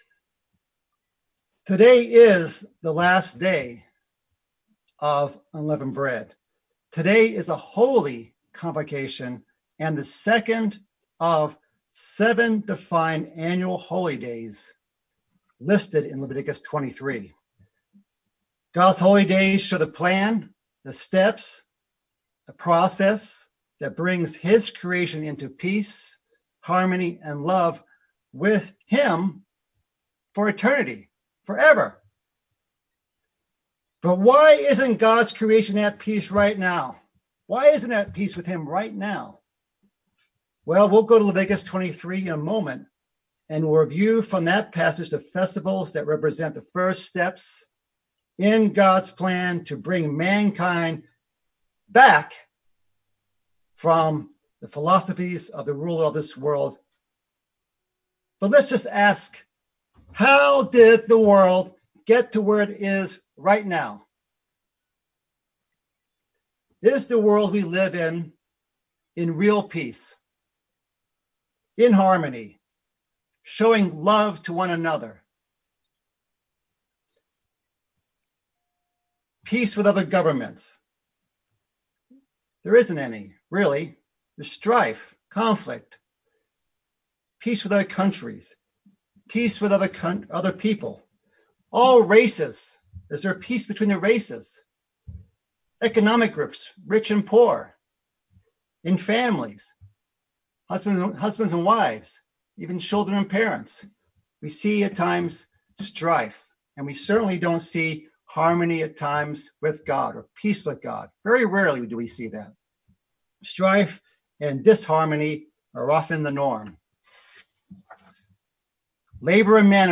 Unleavened Bread Holy Day Services Egypt Exodus sin God's way of life Studying the bible?